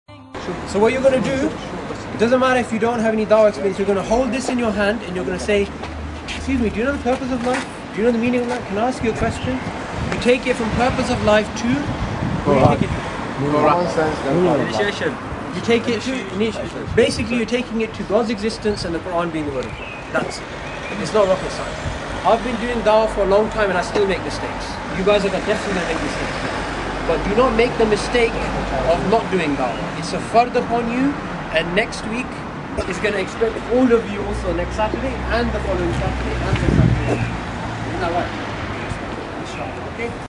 this mp3 of a da'wah excursion made by the iERA team to Middlesbrough in 2012 (published on Youtube).